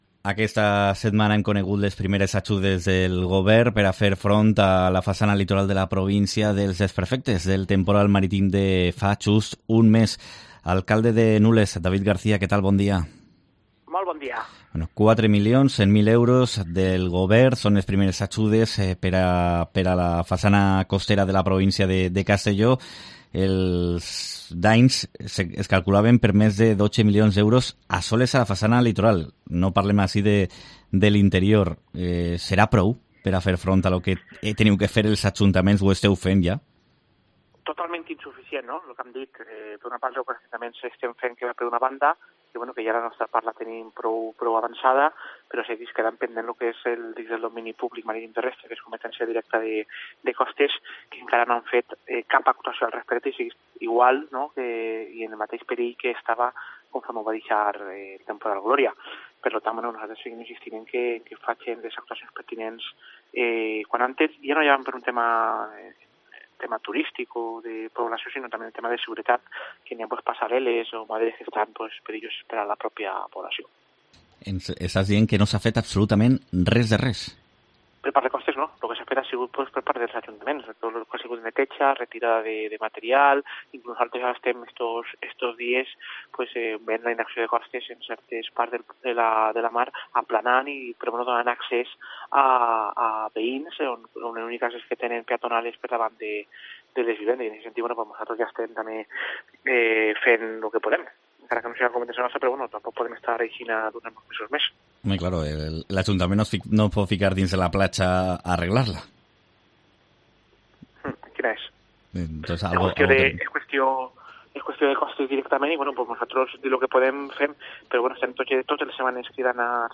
Entrevista al alcalde de Nules, David García.